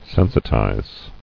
[sen·si·tize]
Sen"si*ti`zer , n. (Photog.) An agent that sensitizes.